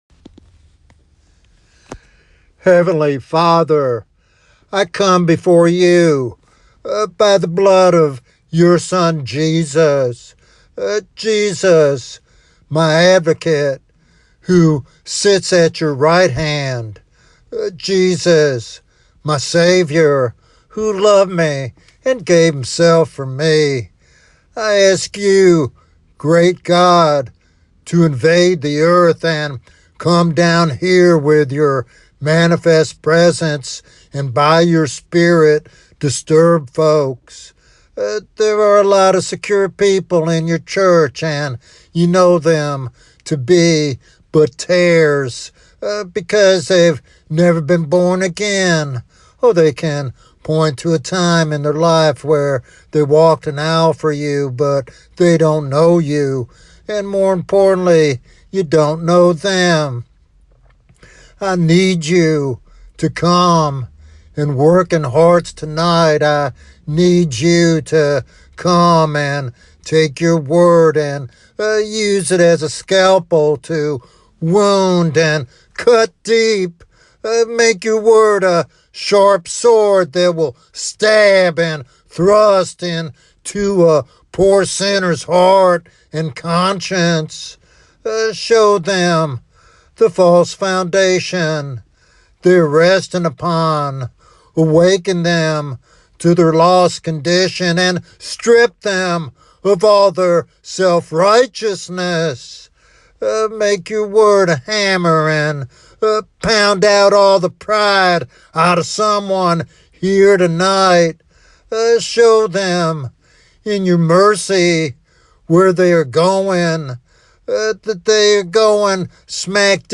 A Real Hell Fire Sermon